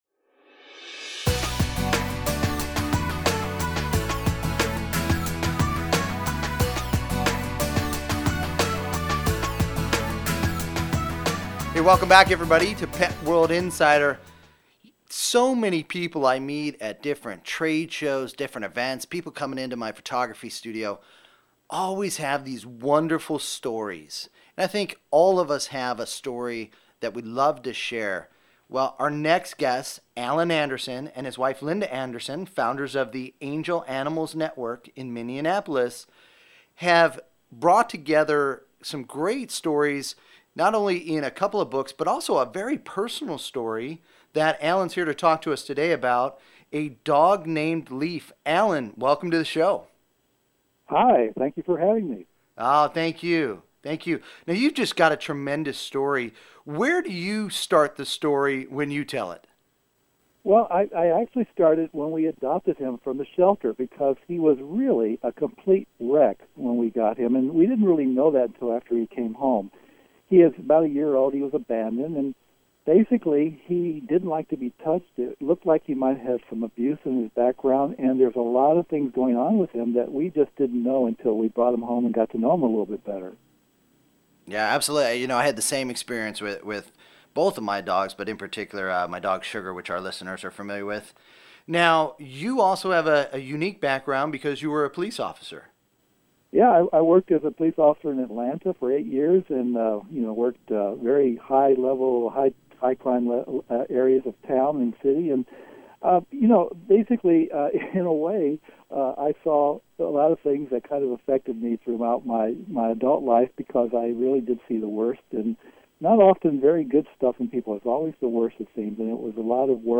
Pet World Insider Radio Segment